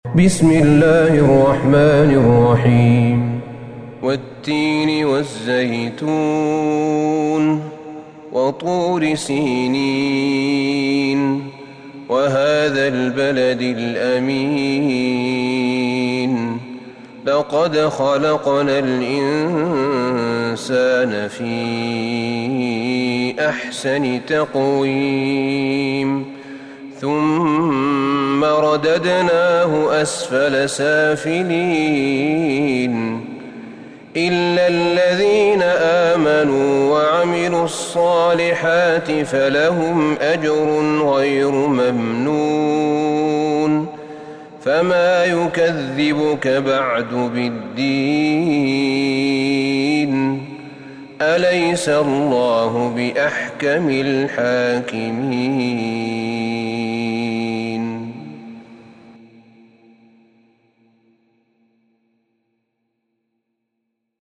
سورة التين Surat At-Tin > مصحف الشيخ أحمد بن طالب بن حميد من الحرم النبوي > المصحف - تلاوات الحرمين